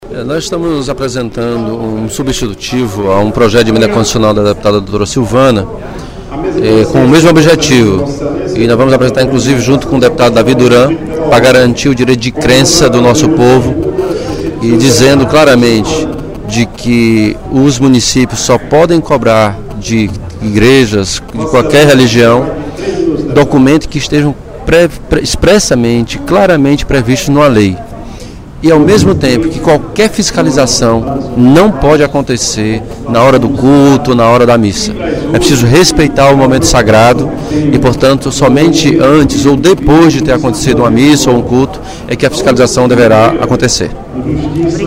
O deputado Elmano Freitas (PT) sugeriu, no primeiro expediente da sessão plenária da Assembleia Legislativa desta quarta-feira (24/06), aos demais parlamentares que participarão da reunião da Comissão de Constituição e Justiça da AL que contribuam com sugestões para a PEC n° 03/2015, de autoria da deputada Dra. Silvana (PMDB).